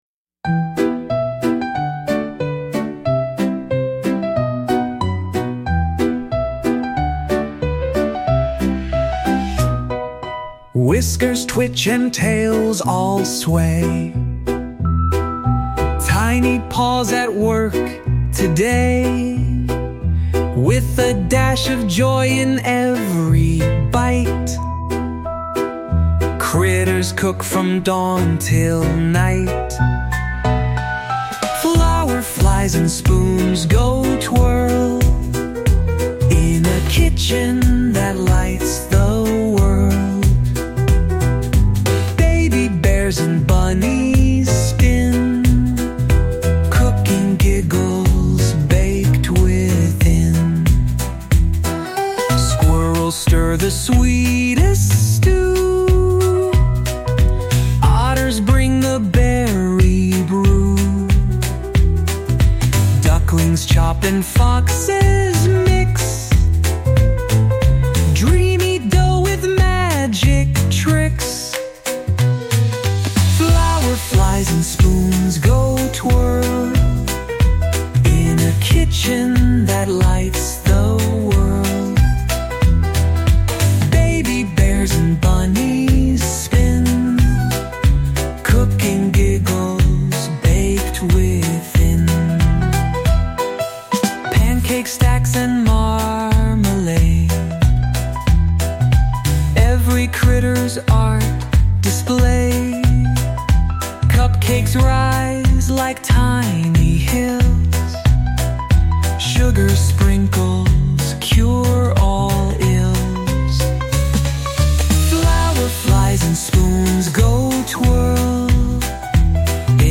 귀여운 아기 동물들이 주방에서 요리하는 모습에 사용할 차분하지만 밝고 명랑한 분위기의 노래
A calm but bright and cheerful song to use for cute baby animals cooking in the kitchen.
SUNO 에서 만든 노래